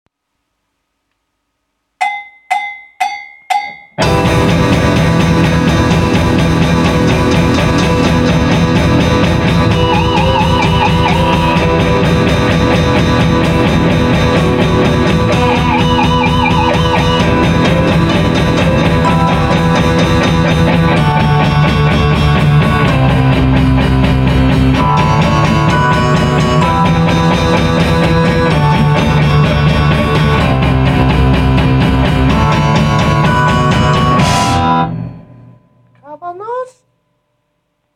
A czasem nawet jest twórczość radosna, spontaniczna i z spod bladego czerepu wyjęta :) Jak np. te dwa, zaawansowane kawałki (UWAGA: jakość próbna).